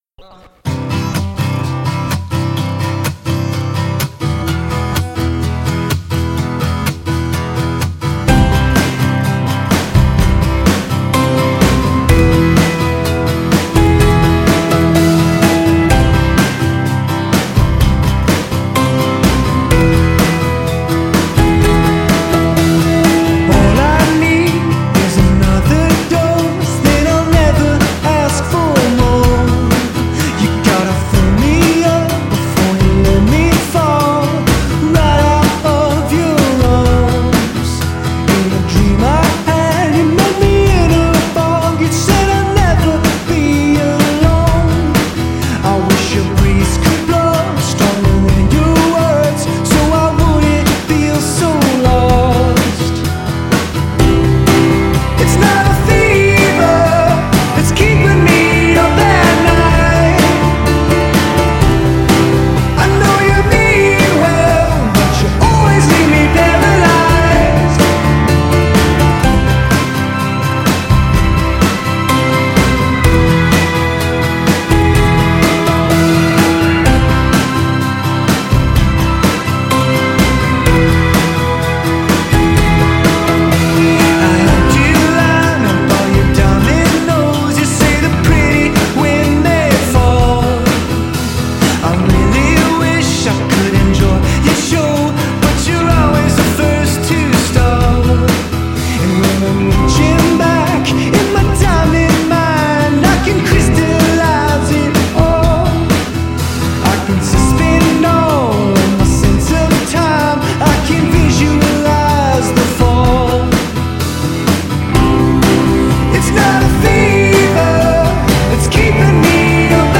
wall-of-reverb pop sheen
A few of these choruses are the real stick-in-the-head type.